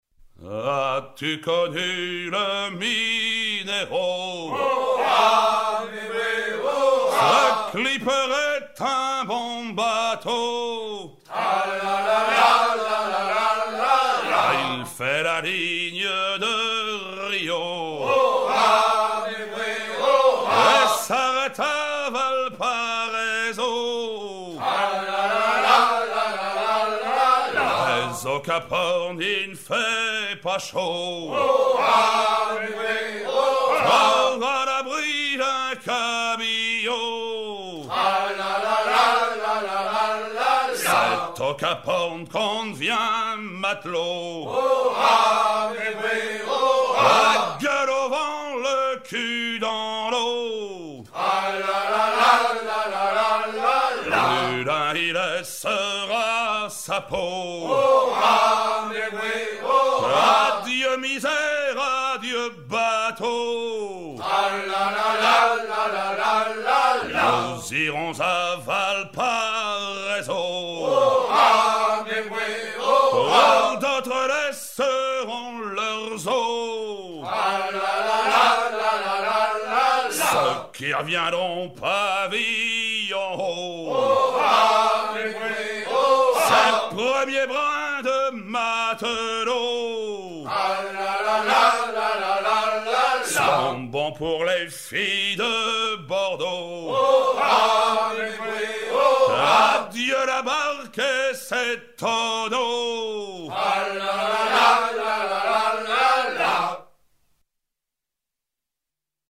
maritimes
Pièce musicale éditée